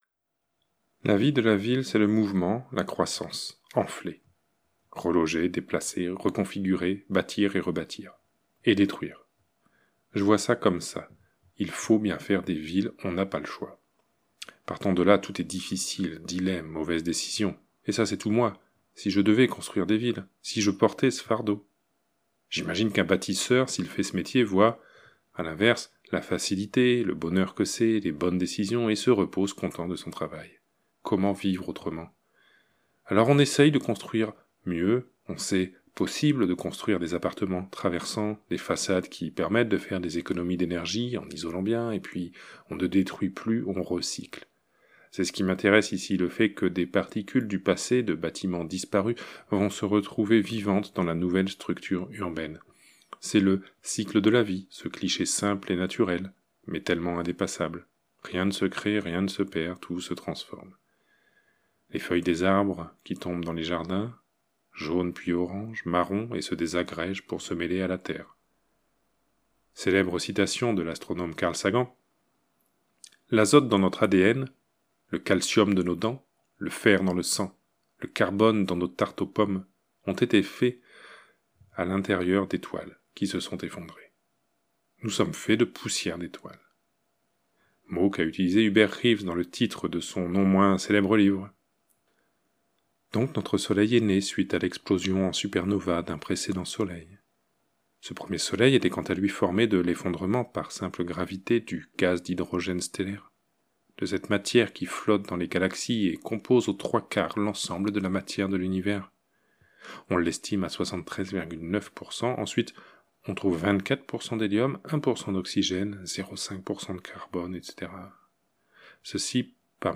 Extrait de Lisières limites, lu